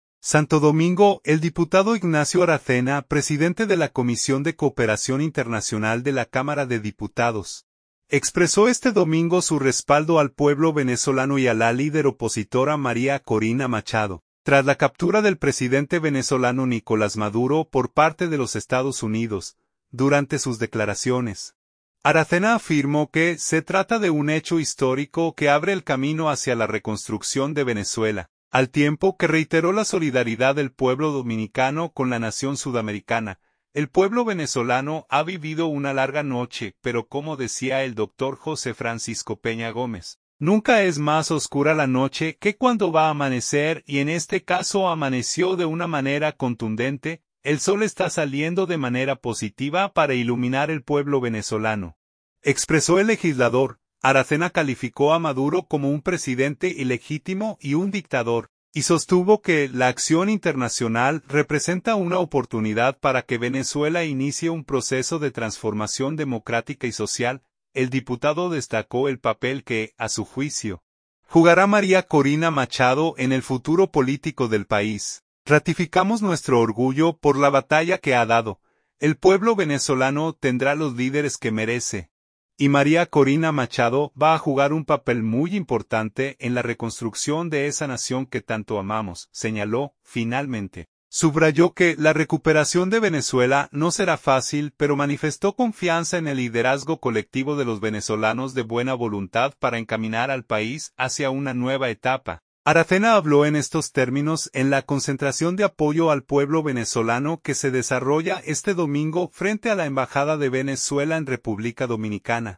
Aracena habló en estos términos en la concentración de apoyo al pueblo venezolano que se desarrolla este domingo frente a la embajada de Venezuela en Republica Dominicana.